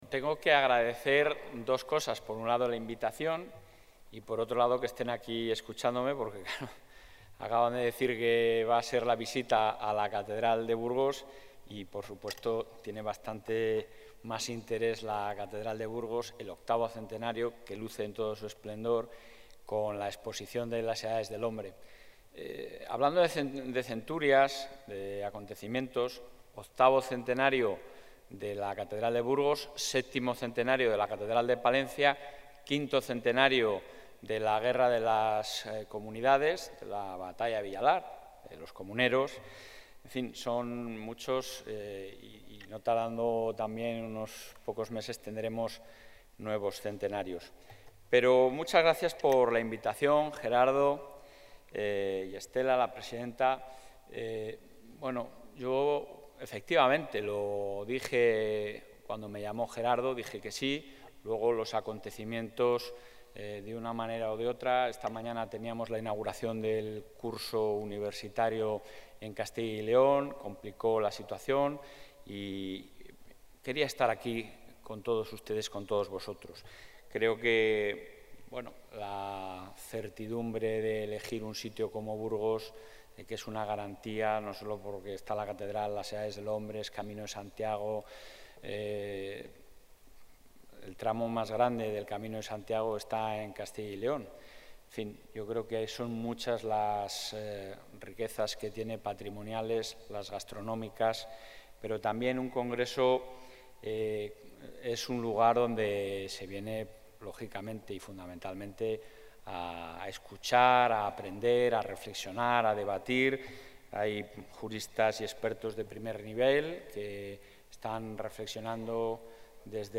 Intervención del presidente de la Junta.
Así lo ha afirmado en la inauguración del XVI Congreso Tributario que se celebra en Burgos, donde ha reclamado que todas las administraciones apuesten por una política fiscal favorable con el mundo rural.